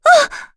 Yuria-Vox_Damage_kr_03.wav